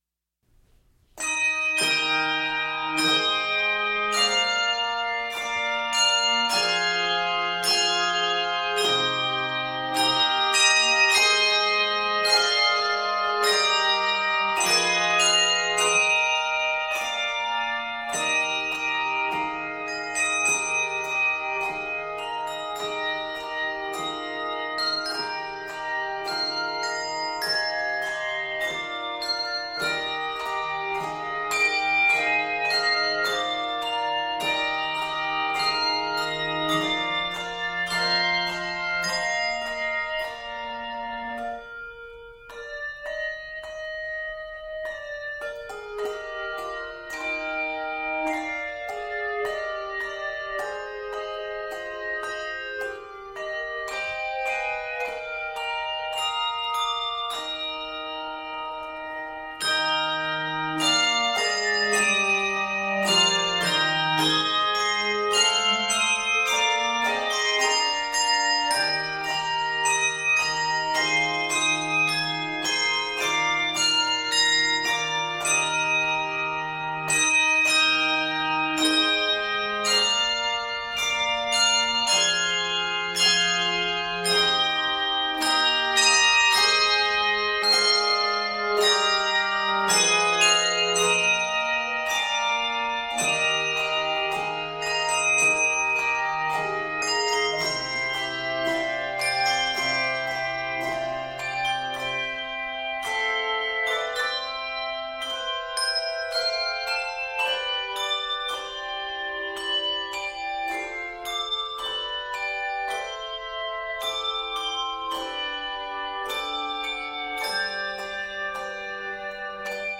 This powerful arrangement of the hymn